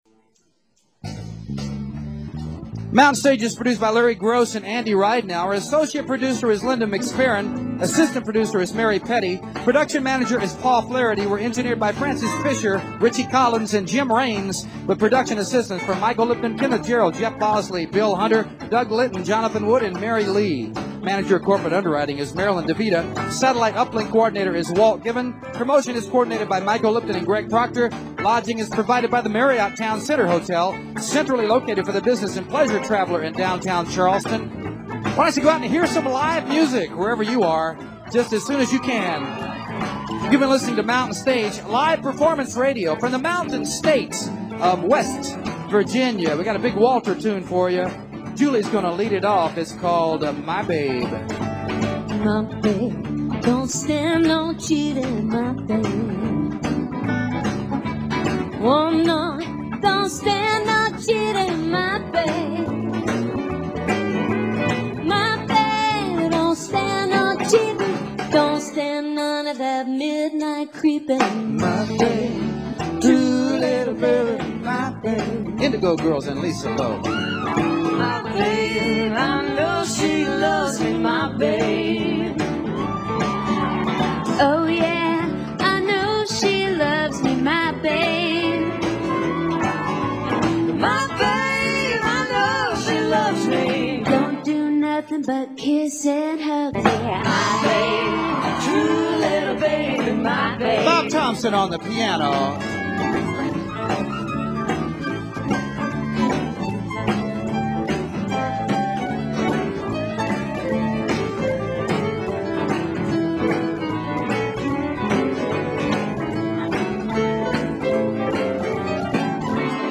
(group)